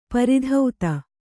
♪ pari dhauta